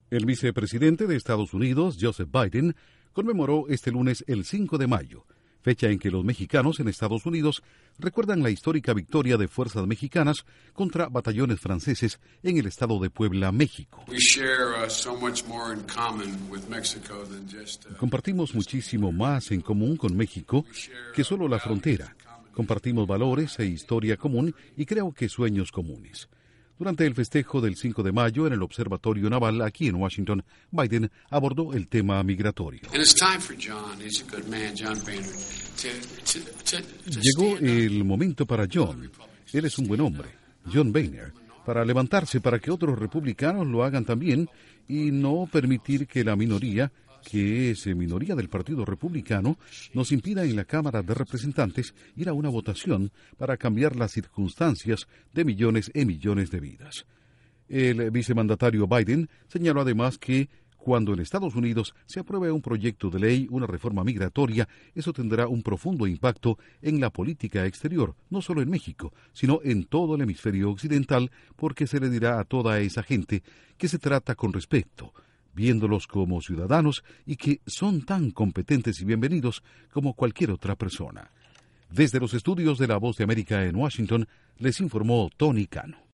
Con un llamado a legisladores republicanos para que den impulso al proyecto sobre reforma migratoria, el vicepresidente de Estados Unidos, Joseph Biden, festejó el 5 de Mayo en el Observatorio Naval de la capital estadounidense, uniéndose a las celebraciones de los mexicanos que viven en la nación. Informa desde los estudios de la Voz de América en Washington